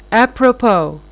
a prɔpo/ [Fr. à propos, f. à to + propos purpose.]